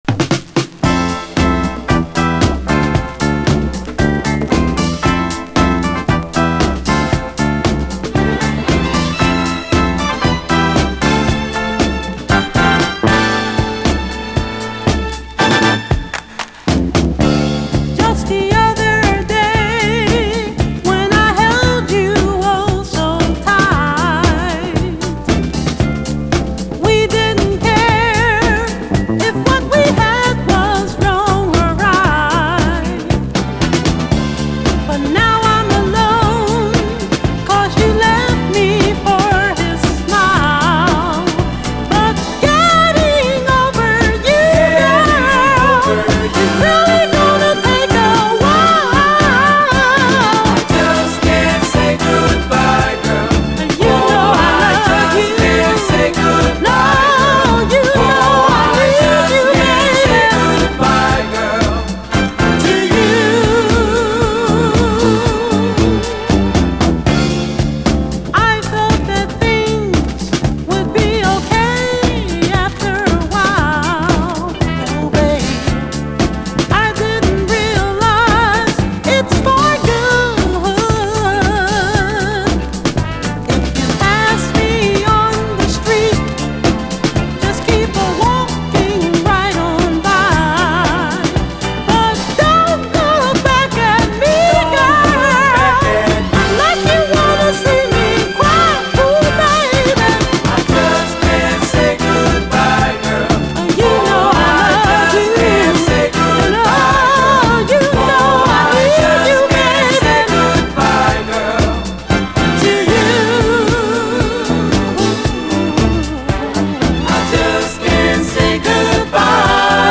メロウでロマンティックなズーク＝”ズーク・ラブ”アルバム！
90’S R&Bと融合したサウンドが素晴らしいです！